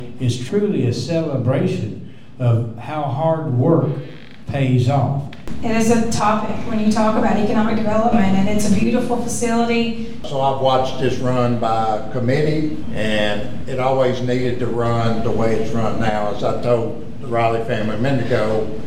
Glasgow Mayor Henry Royse, Barren County Judge Executive Jamie Bewley Byrd, and Glasgow City Councilman Patrick Gaunce also thanked